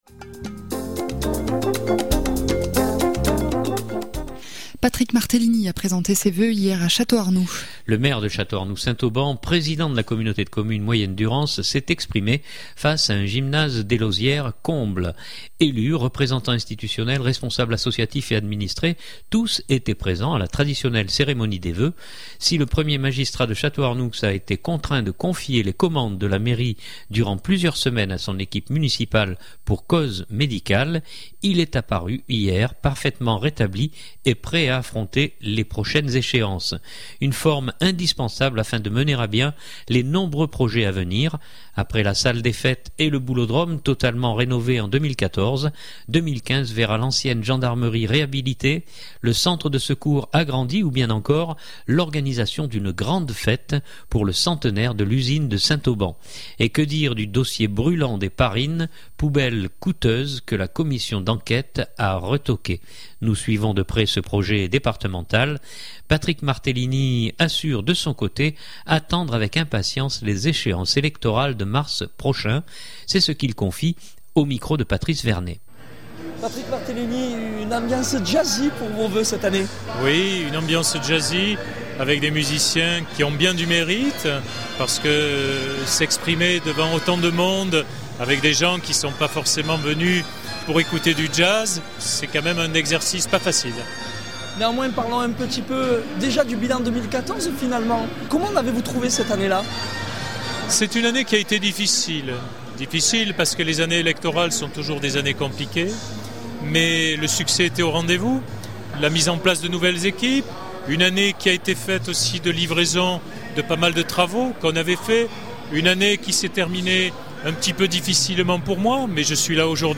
Le Maire de Château-Arnoux St Auban Président de la Communauté de Communes Moyenne Durance, s’est exprimé face à un gymnase des Lauzières comble.